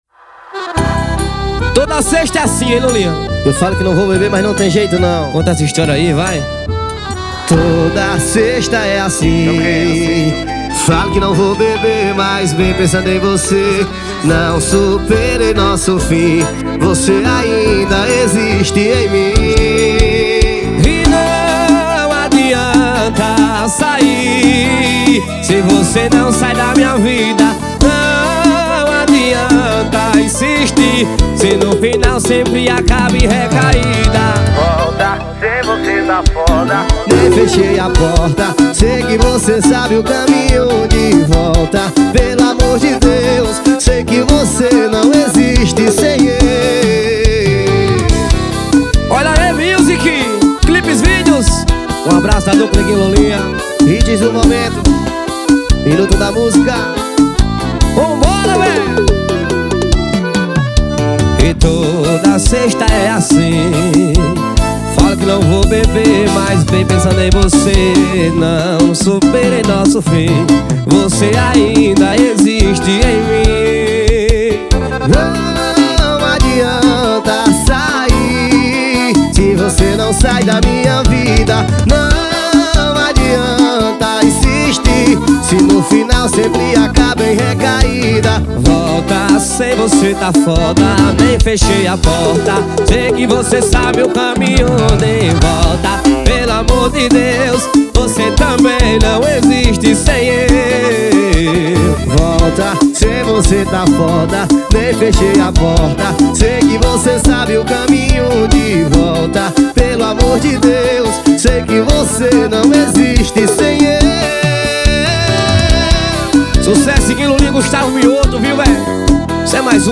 2024-02-14 18:17:20 Gênero: Forró Views